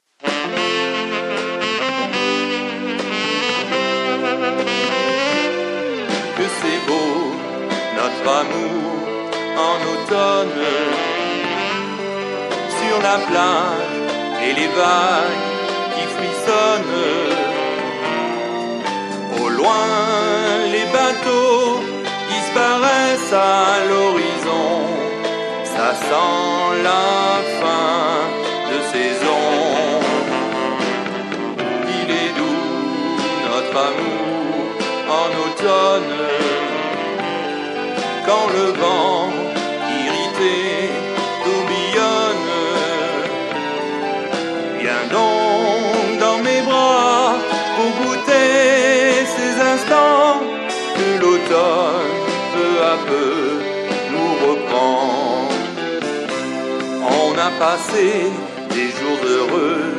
(Slow-rock)